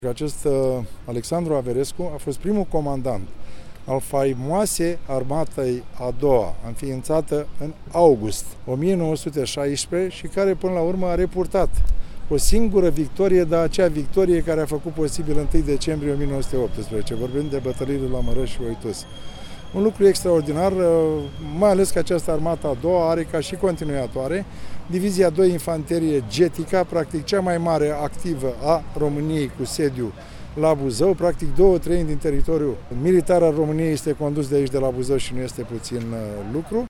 Zeci de buzoieni, precum și șefi ai administrației locale și județene au participat astăzi la un ceremonial militar și religios  cu ocazia dezvelirii bustului mareșalului Alexandru Averescu, primul comandant al Armatei a 2-a. La acest eveniment au fost prezenți prefectul județului, Carmen Ichim, președintelui Consiliului Județean Petre Emanoil Neagu și vicepreședinții instituției Adrian Petre și Romeo Lungu, primarul Constantin Toma și viceprimarul Ionuț Apostu, europarlamentarul PMP Eugen Tomac (născut în Basarabia, care a contribuit financiar la realizarea lucrării), comandantul Diviziei 2 Infanterie „Getica”, generalul Gheorghiță Vlad, generalul de brigadă Marian Bodea, șeful direcției Operații din cadrul Statului Major al Apărării, numeroși militari, preoți și locuitori ai municipiului Buzău.